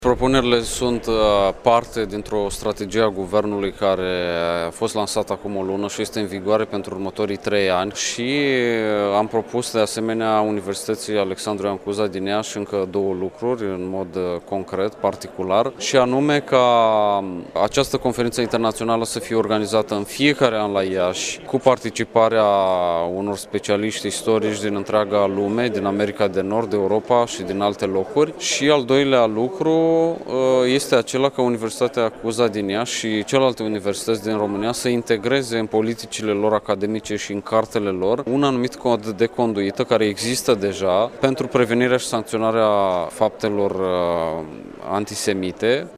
De astăzi, timp de două zile, la Iaşi, se desfăşoară acţiunile comemorative care marchează 80 de ani de la Pogromul împotriva evreilor.
La Universitatea “Alexandru I. Cuza” a fost organizată Conferința internaţională ‘Iaşi 1941-2021: Memorie, Asumare, Uitare’.